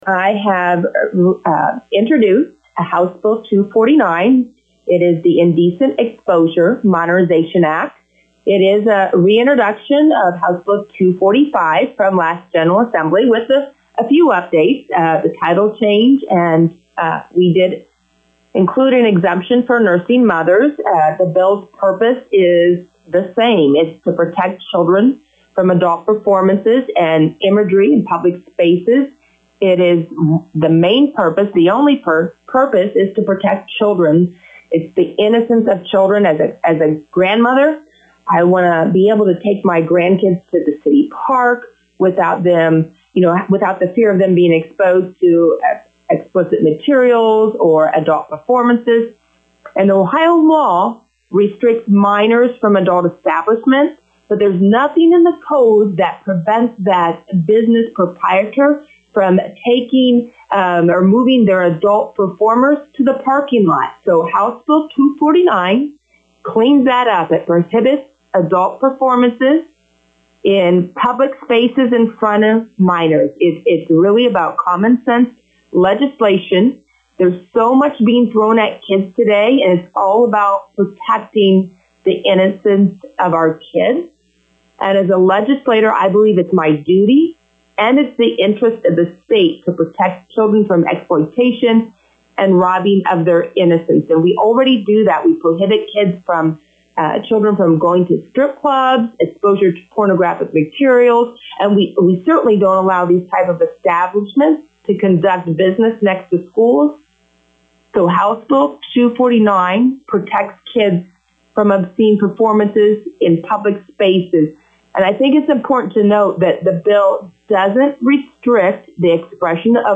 To hear comments with Rep. Angie King: